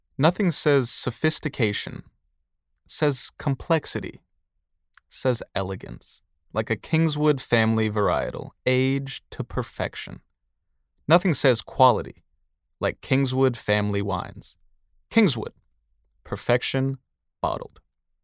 Male
Yng Adult (18-29)
Radio Commercials
Words that describe my voice are Versatile, Calm, Natural.